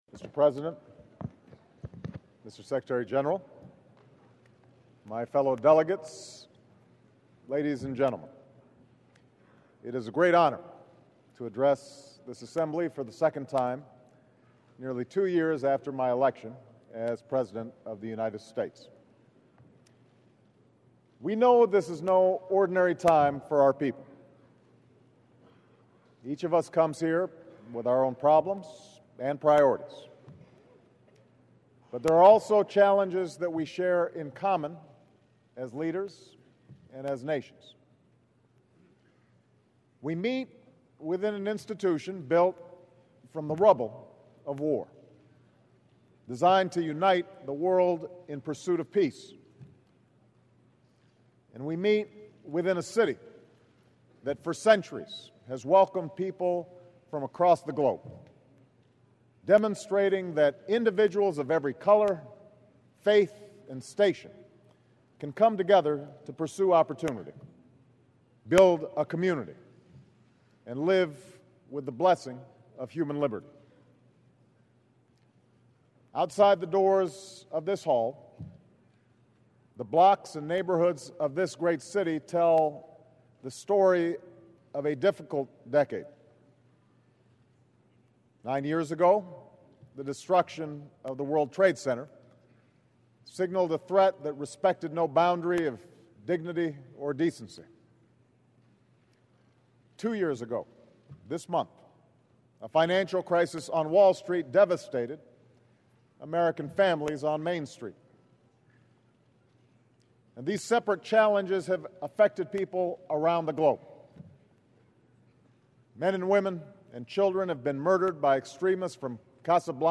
U.S. President Barack Obama addresses the United Nations General Assembly